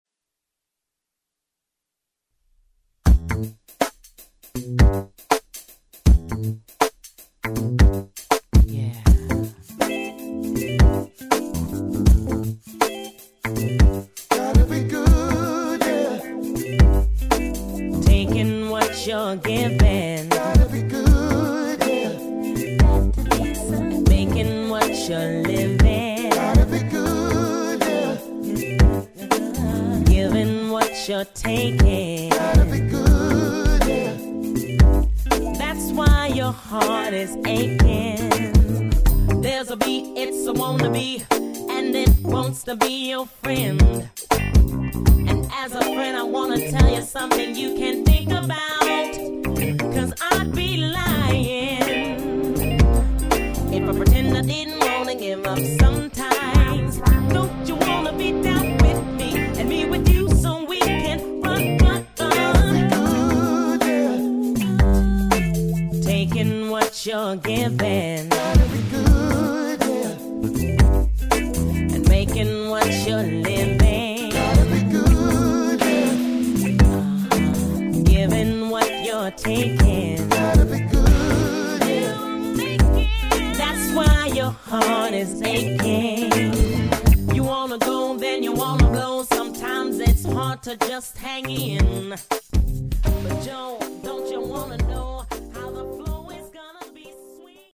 R&B CD project